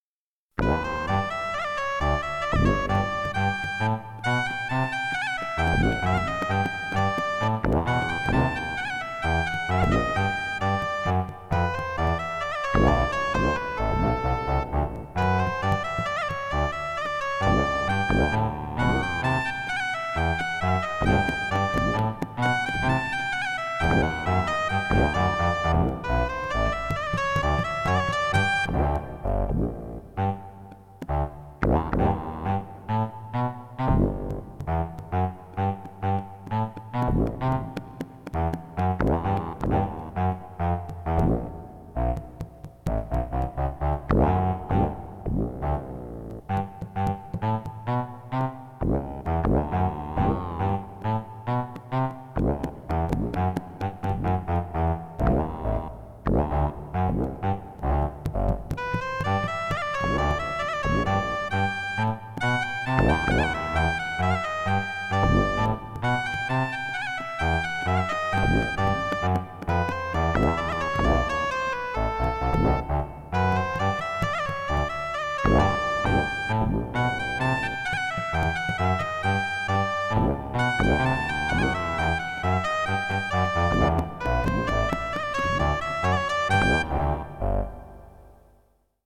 as recorded from the original Roland MT-32 score!